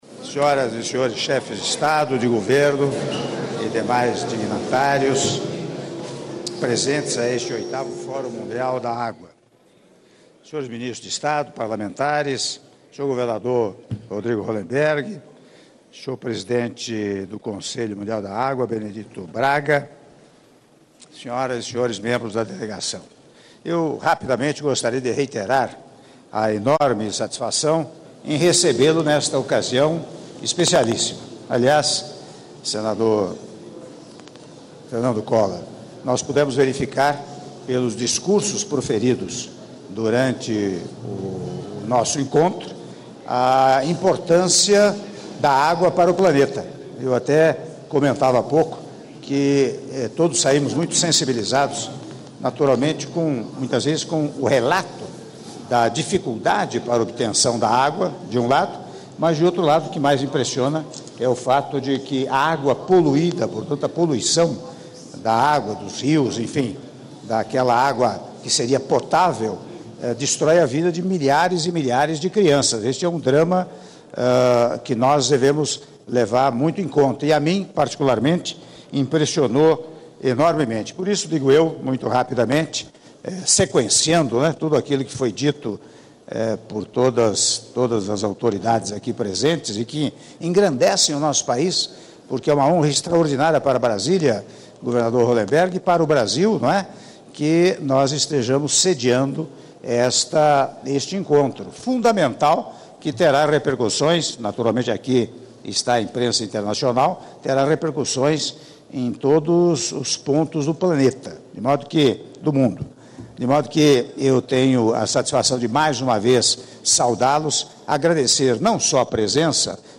Áudio do discurso do Presidente da República, Michel Temer, durante Almoço em homenagem aos Chefes de Delegação participantes do VIII Fórum Mundial da Água - (02min36s) - Brasília/DF